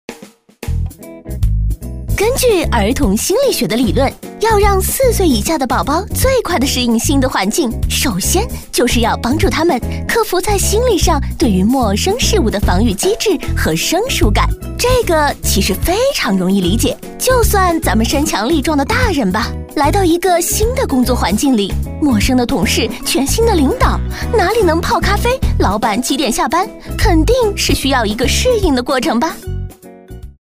当前位置：首页 > 配音题材 > 病毒配音
女声配音